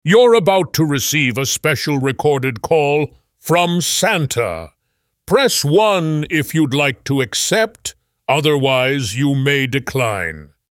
santarecordedmessage.mp3